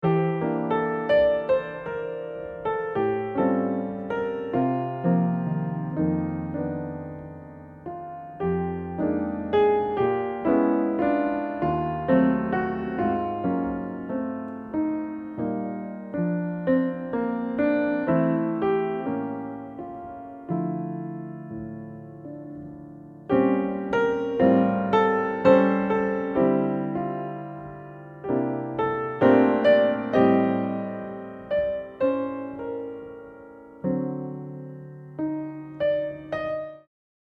piano introduction